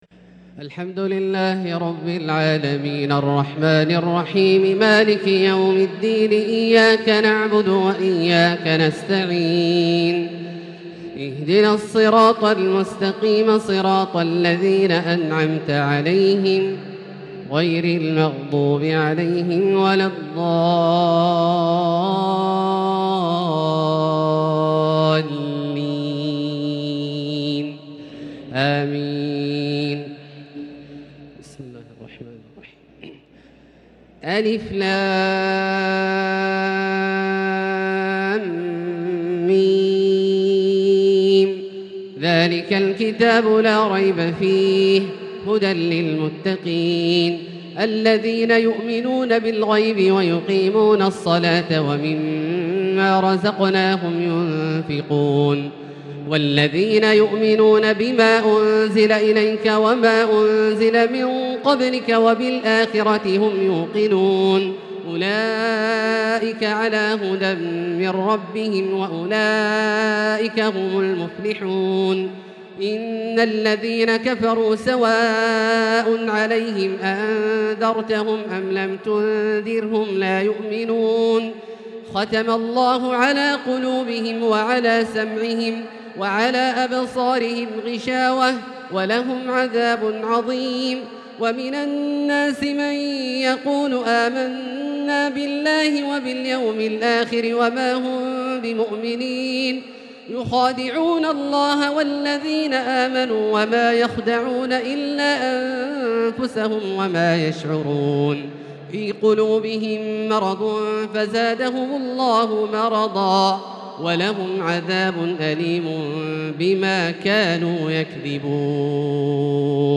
تراويح ليلة 1 رمضان 1443هـ من سورة البقرة (1-86) Taraweeh 1st night Ramadan 1443H > تراويح الحرم المكي عام 1443 🕋 > التراويح - تلاوات الحرمين